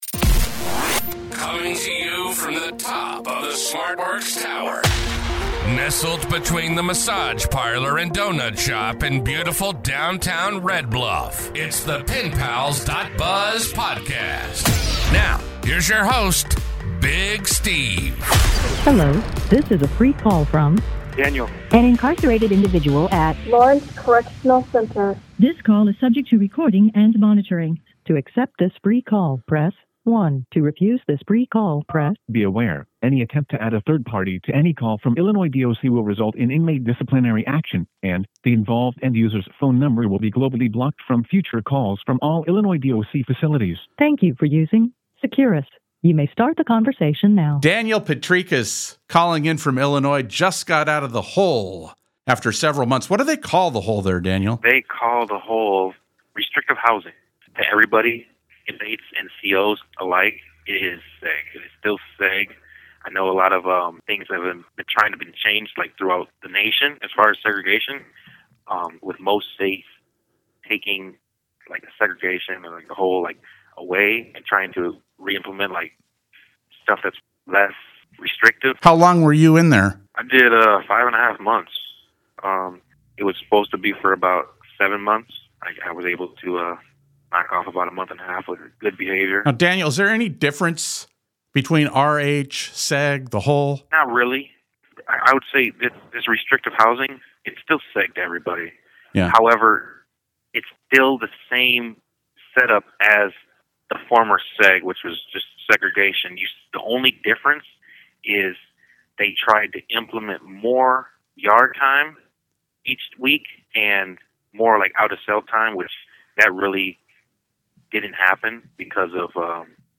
But which ones are reliable, and which ones should be avoided? In this podcast episode, we interview Illinois inmate